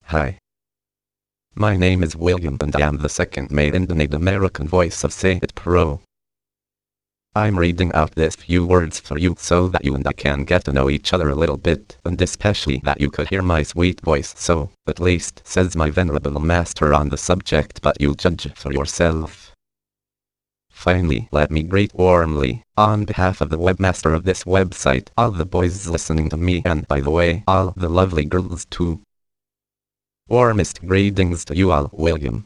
Texte de démonstration lu par William, deuxième voix masculine américaine de LogiSys SayItPro (Version 1.70)
Écouter la démonstration de William, deuxième voix masculine américaine de LogiSys SayItPro (Version 1.70)